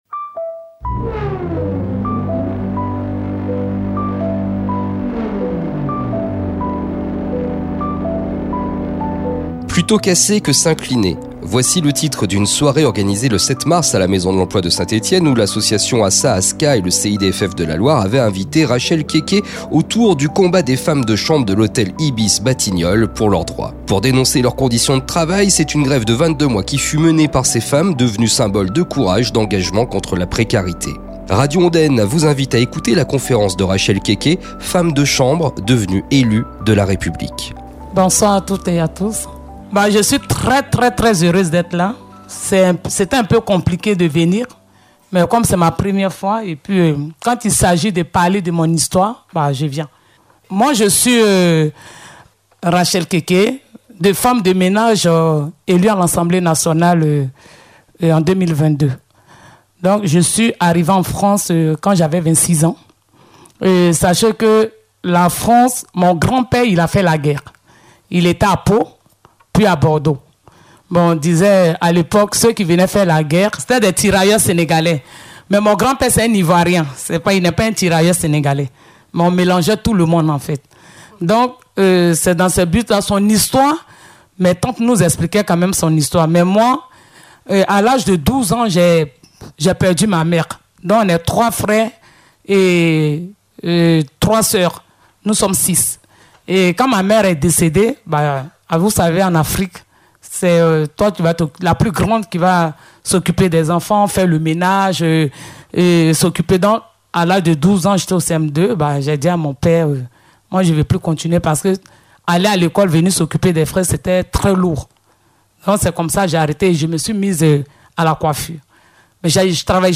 CONFÉRENCE DE RACHEL KÉKÉ à la Maison de l’Emploi de Saint-Etienne. – Radio Ondaine 90.9 FM